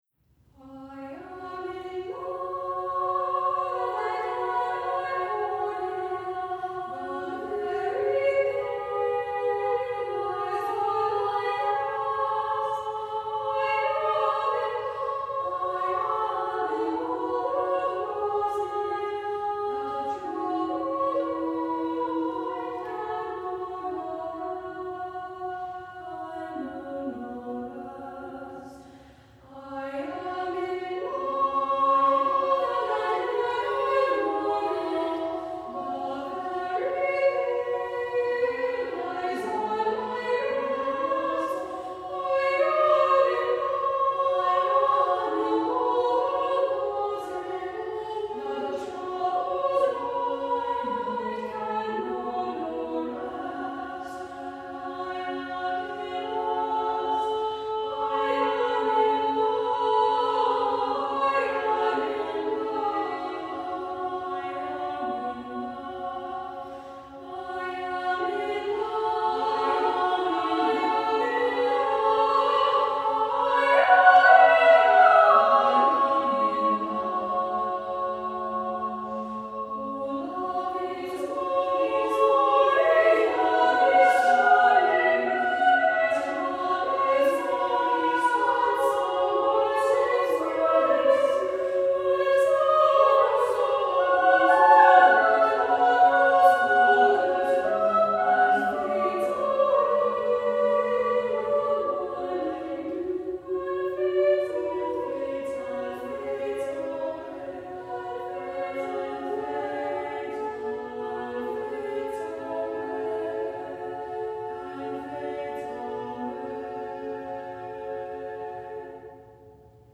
Composer: Folk Song - Southern USA
Voicing: SSA